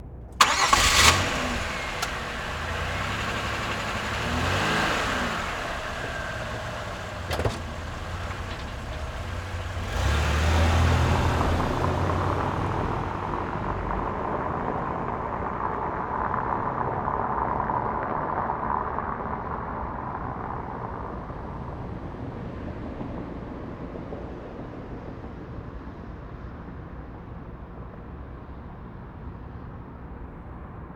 Tires CS Car Pont MS Away01.wav